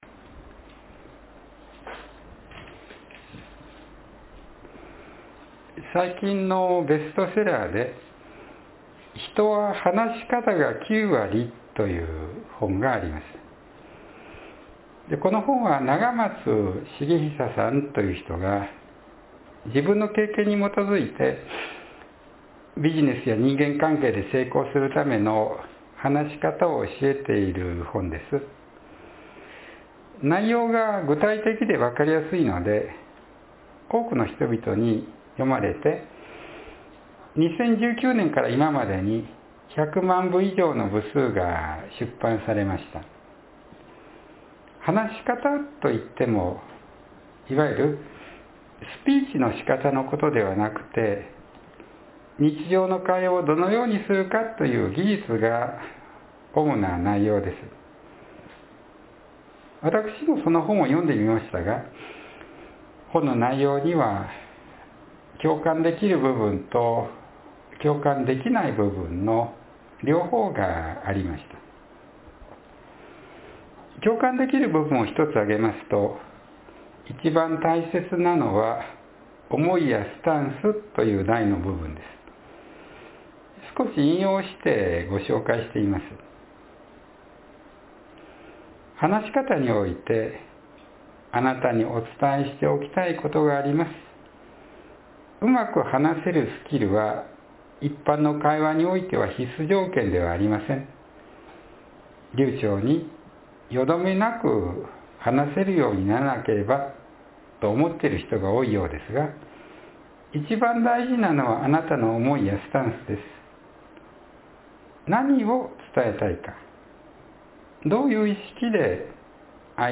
（7月17日の説教より）